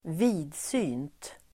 Uttal: [²v'i:dsy:nt]